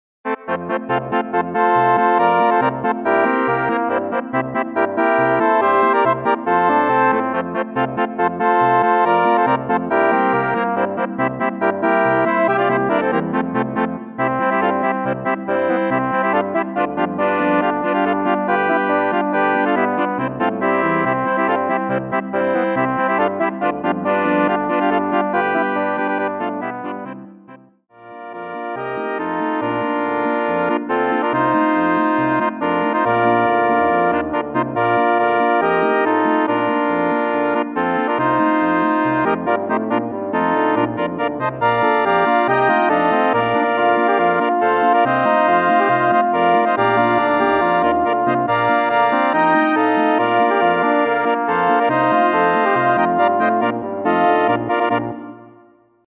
Bezetting Tanzlmusi